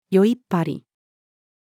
宵っぱり-female.mp3